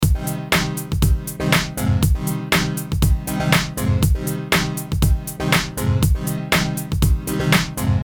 The final effect in action
The two sounds together with the final effect in place